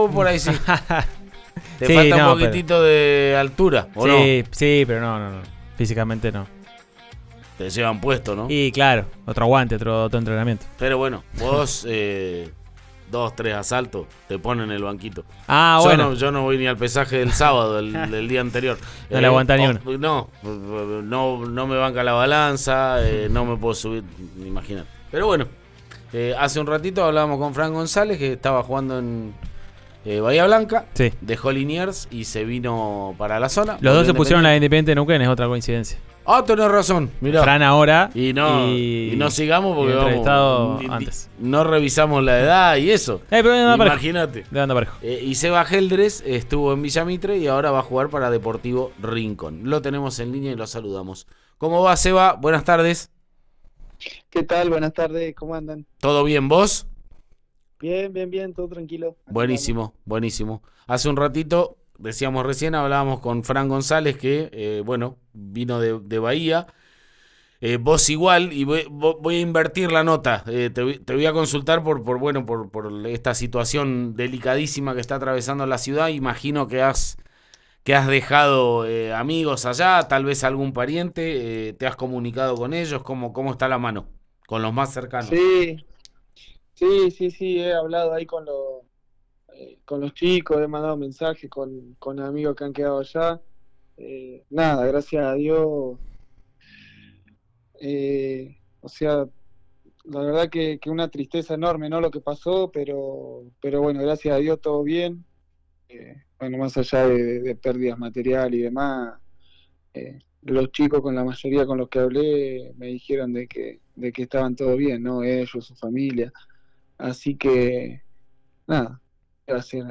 El delantero habló en Río Negro Radio antes del duelo con el Albinegro del sábado.